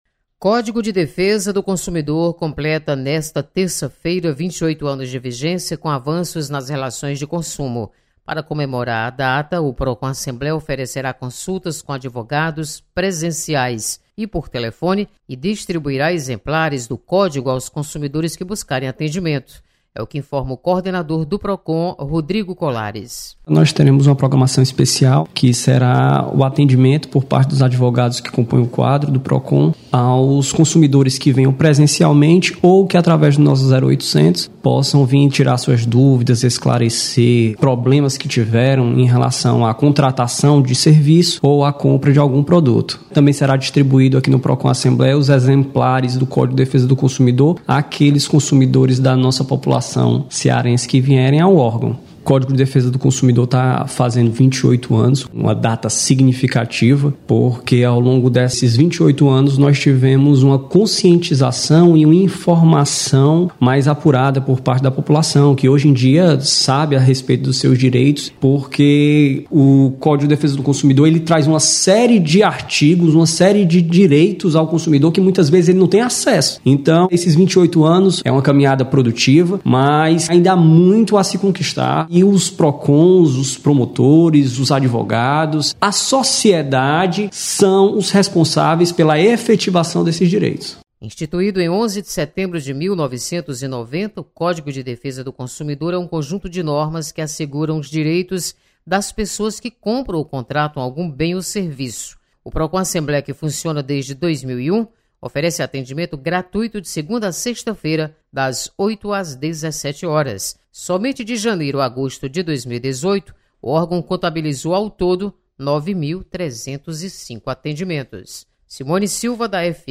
Procon Assembleia comemora 20 anos do Código de Defesa do Consumidor. Repórter